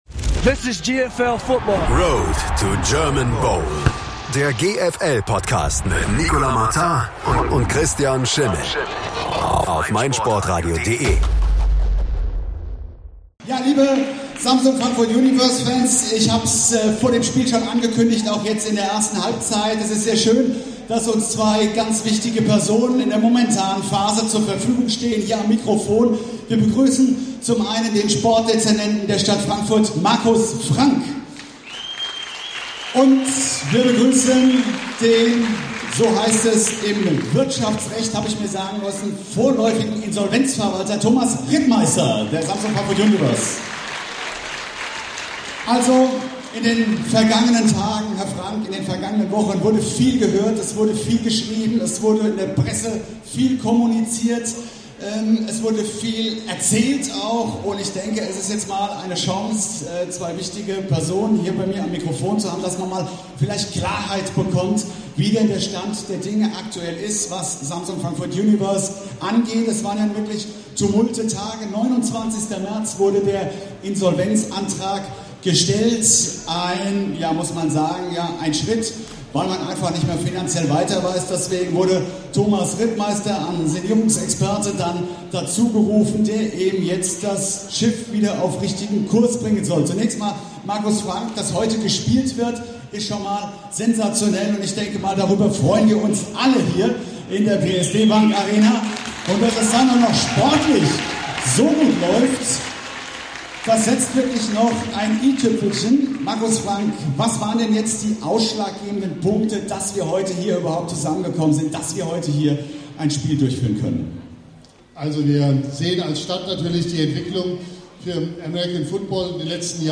interviewt.